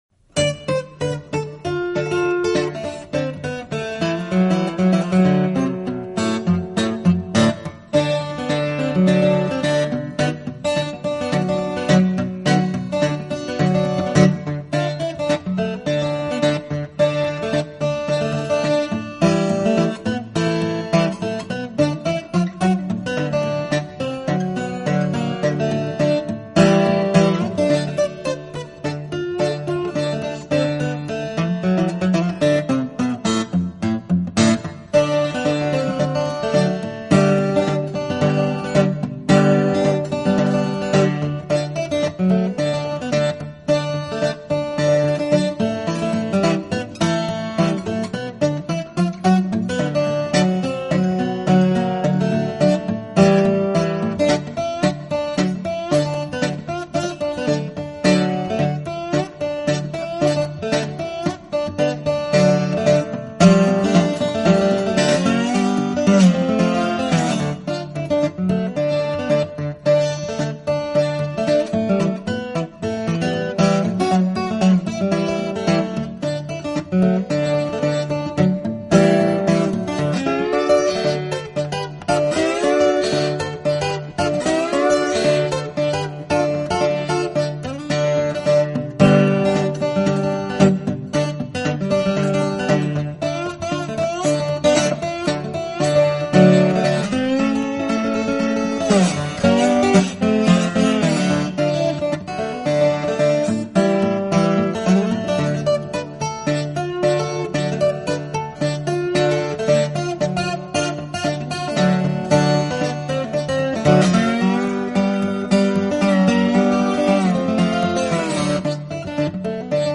非常棒的一张纯原声布鲁斯吉他专集。